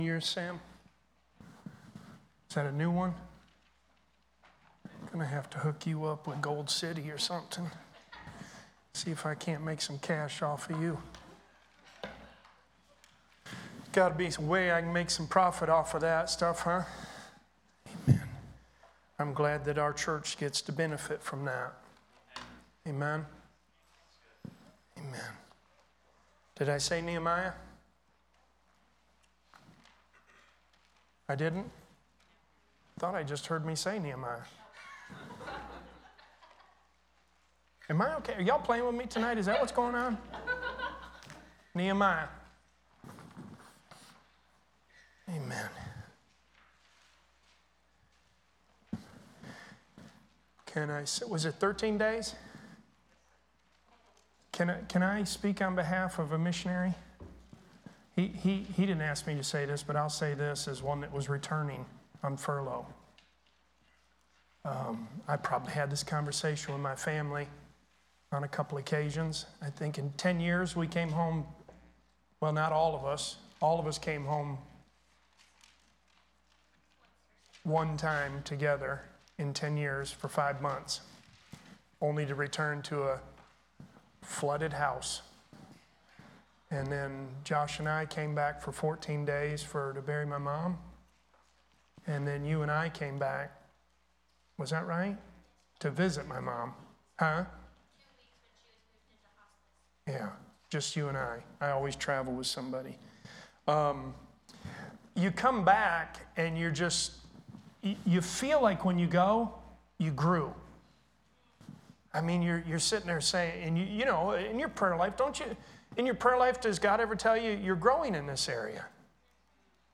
Sermons | Graham Road Baptist Church